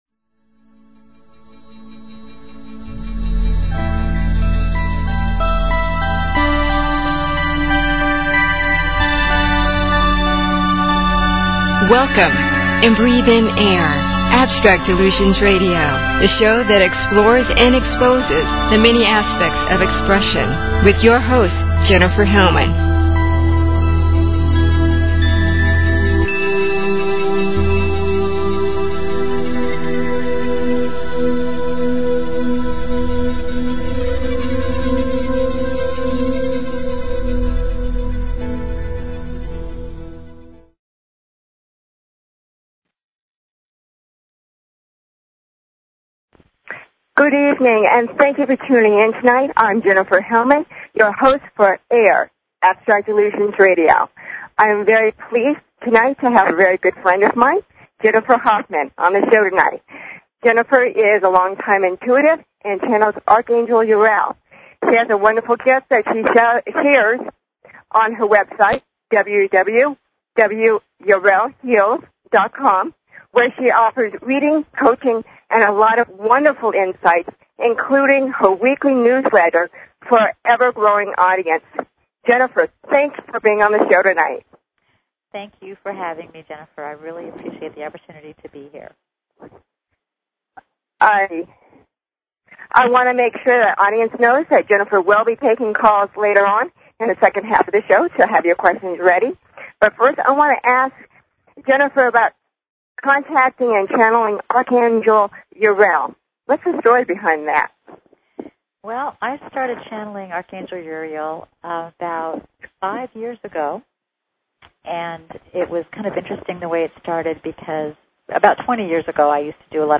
Talk Show Episode, Audio Podcast, Rock_My_Soul_Radio and Courtesy of BBS Radio on , show guests , about , categorized as